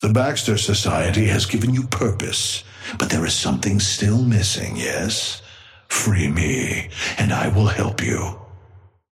Amber Hand voice line - The Baxter Society has given you purpose, but there is something still missing, yes?
Patron_male_ally_shiv_start_04.mp3